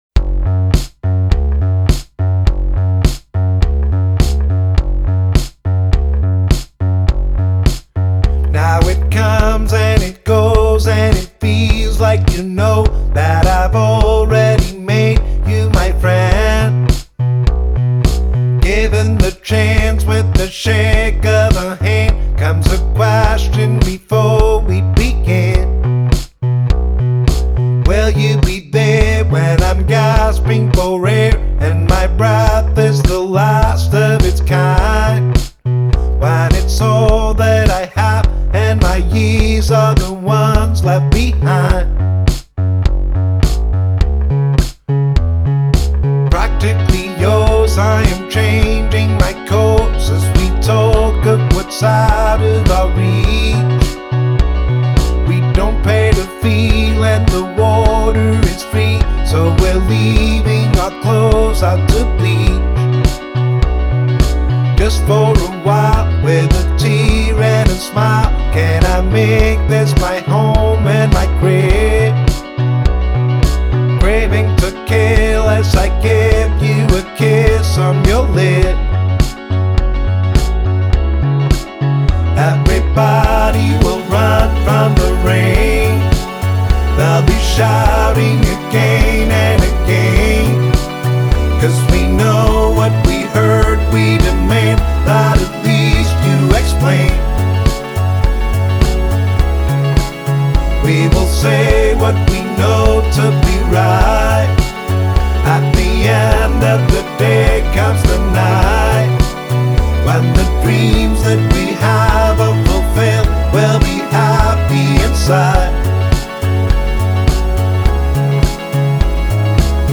Genre: Indie Rock, Alternative